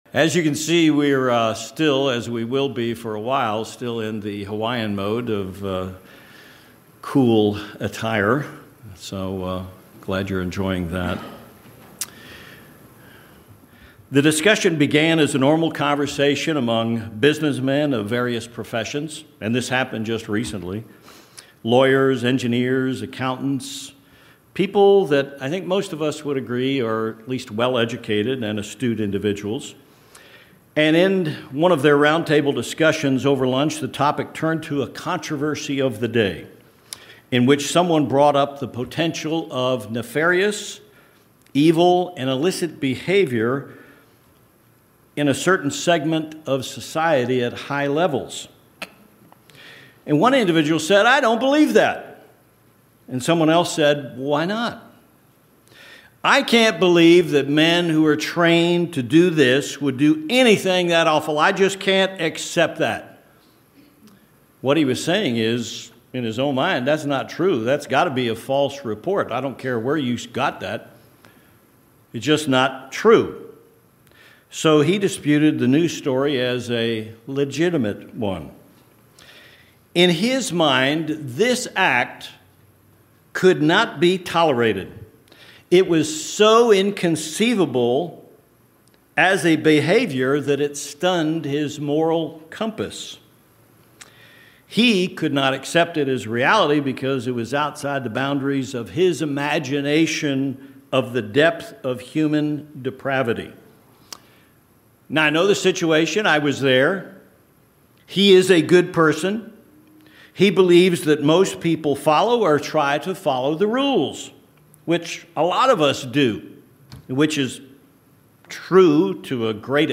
This sermon identifies three types of unbelief within the Church. Understanding these types of unbelief can help us understand the prophetic markers of the future.